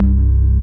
ORGAN-14.wav